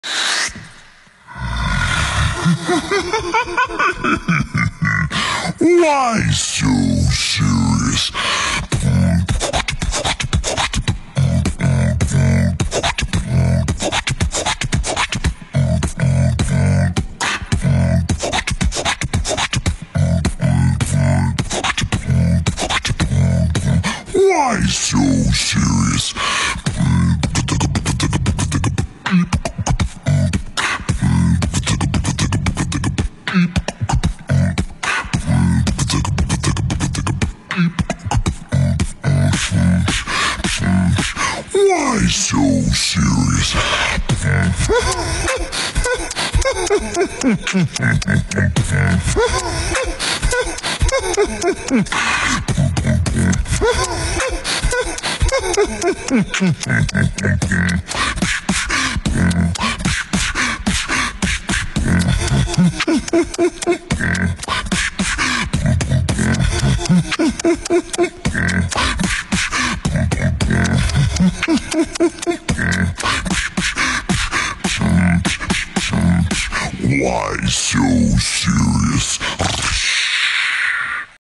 joker beatbox (slowed + reverb).m4a